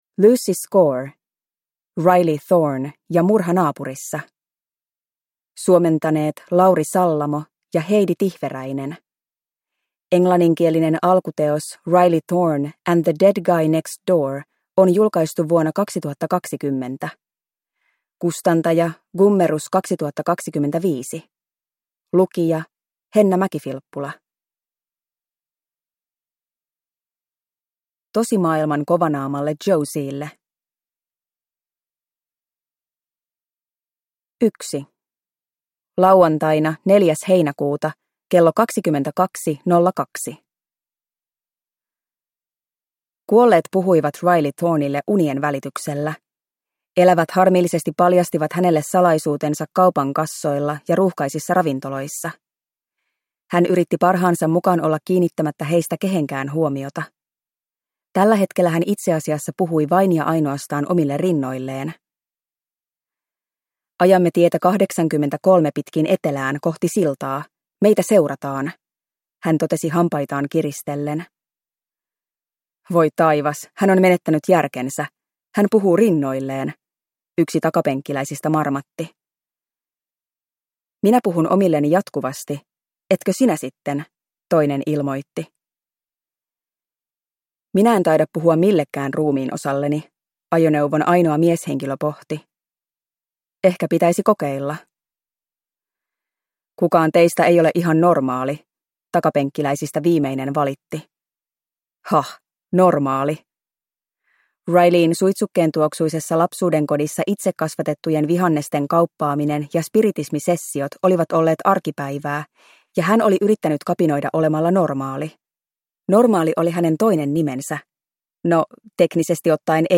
Riley Thorn ja murha naapurissa (ljudbok) av Lucy Score